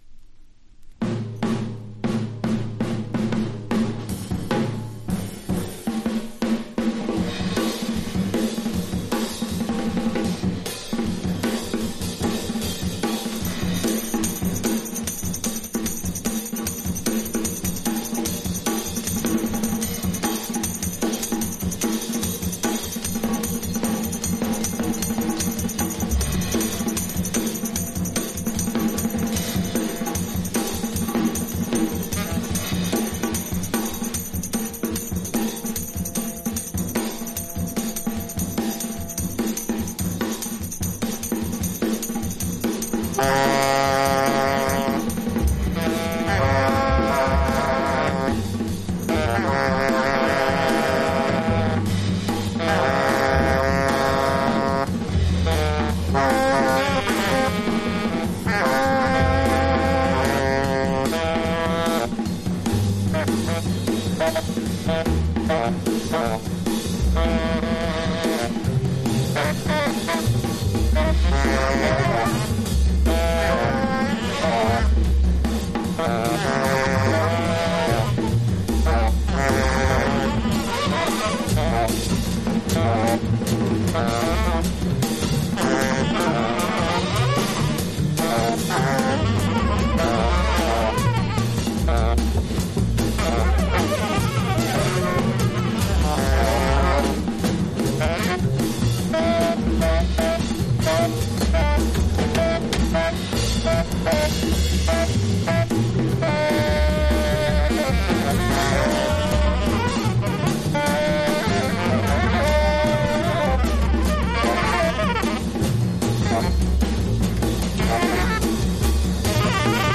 Genre FREE/SPIRITUAL JAZZ